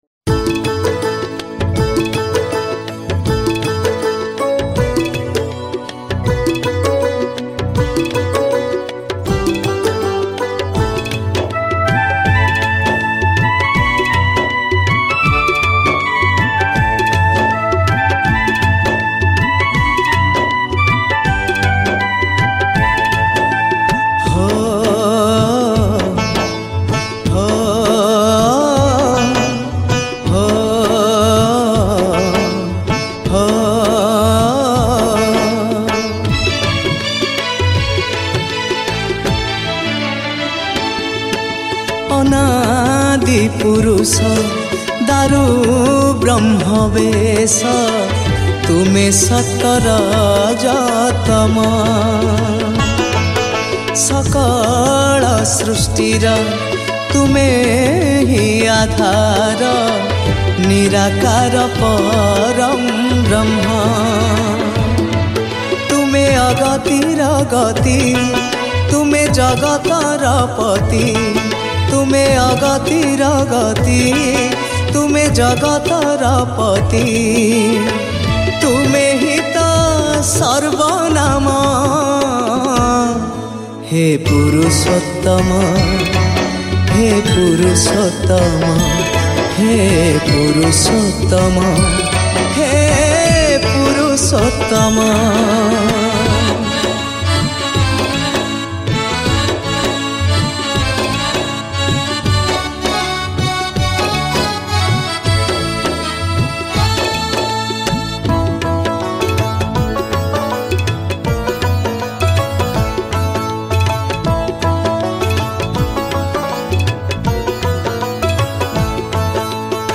Odia New Bhajan Song